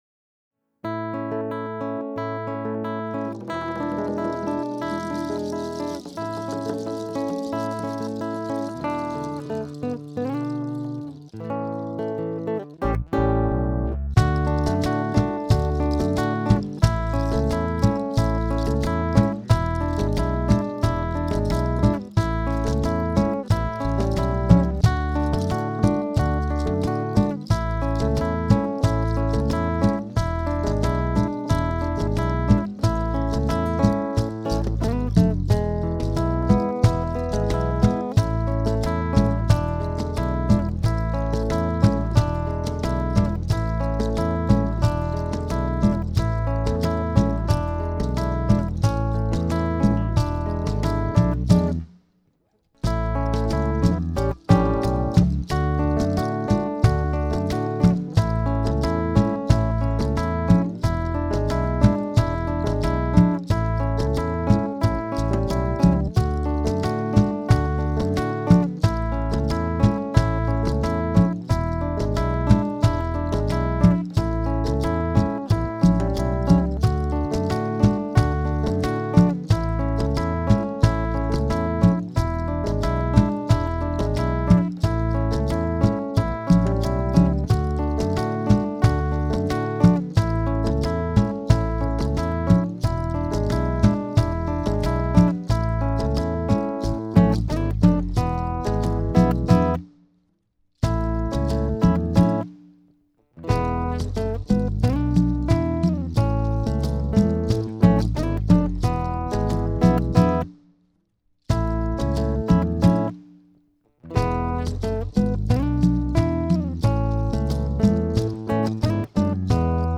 versión instrumental